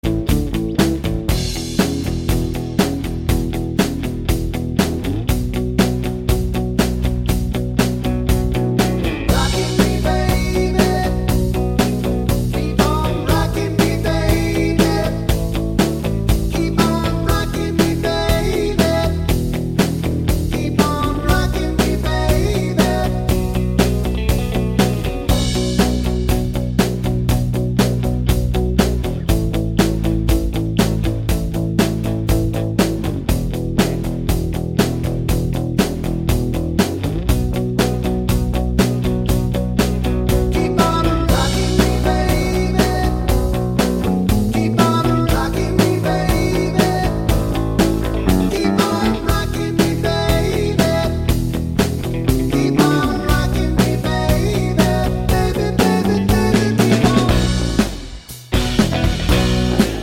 no Backing Vocals Soft Rock 3:13 Buy £1.50